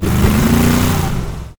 car-engine-load-3.ogg